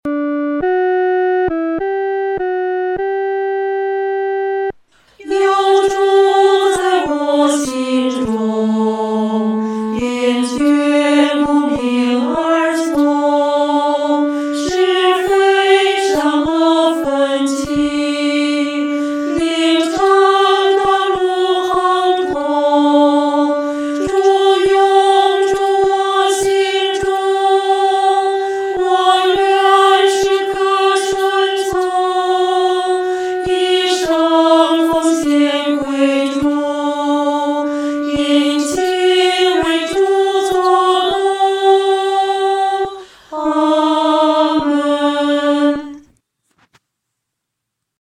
合唱
女低
诗班在二次创作这首诗歌时，要清楚这首诗歌音乐表情是亲切、温存地。